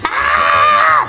Worms speechbanks
ooff3.wav